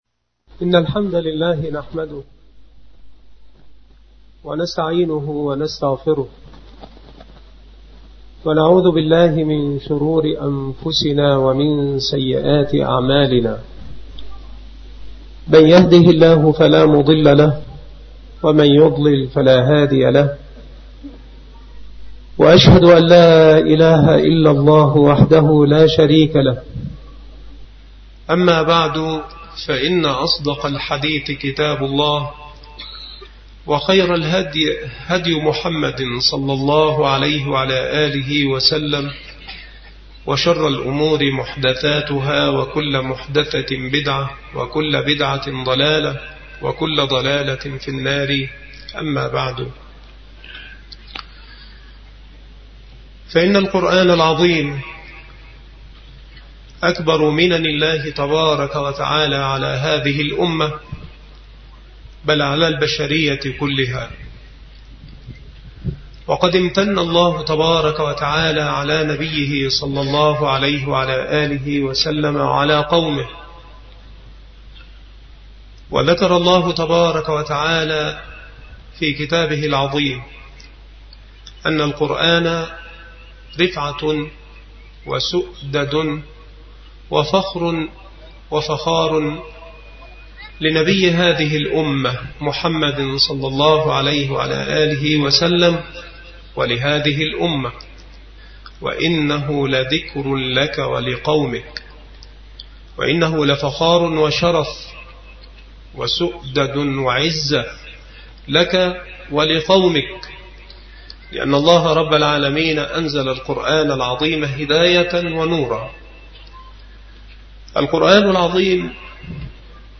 مكان إلقاء هذه المحاضرة بقرية دَرَوَة - مركز أشمون - محافظة المنوفية - مصر